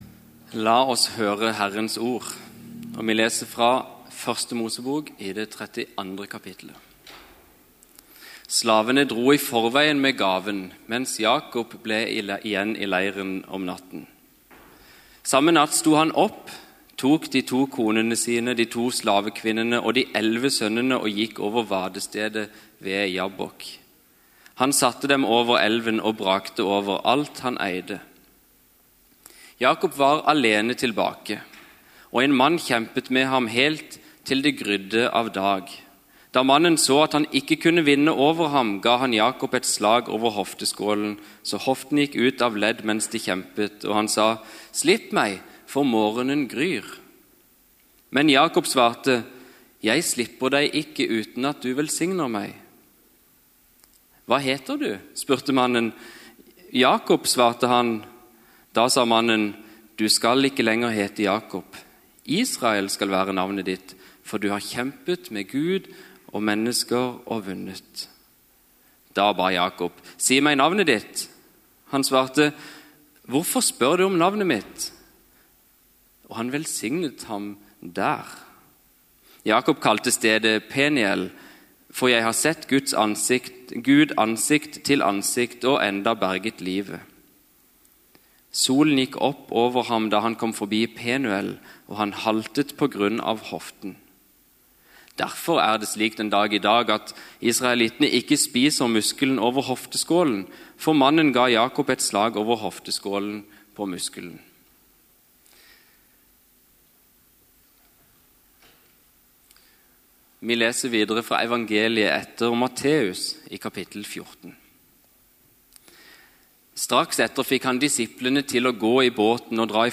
Gudstjeneste 29. oktober 2023, Immanuel - kamp | Storsalen